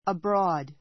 əbrɔ́ːd